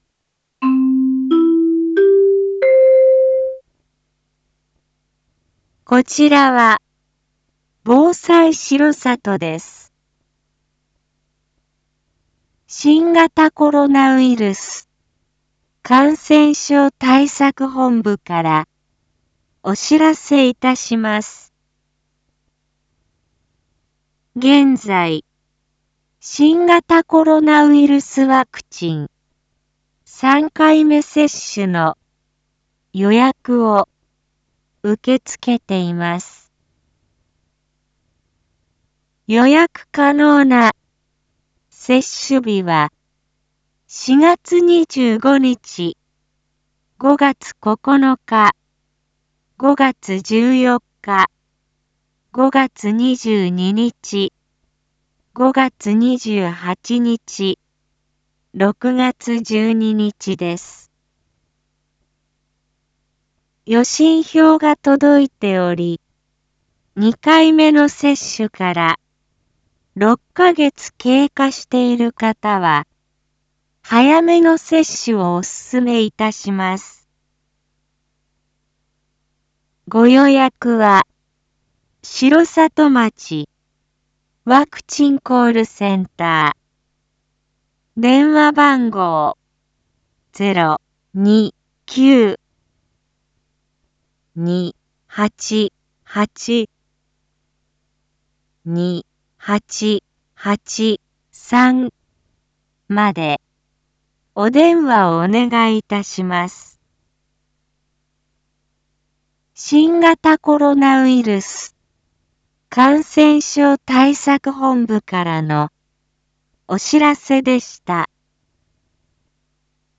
Back Home 一般放送情報 音声放送 再生 一般放送情報 登録日時：2022-04-21 19:02:10 タイトル：３回目ワクチン予約について インフォメーション：こちらは、防災しろさとです。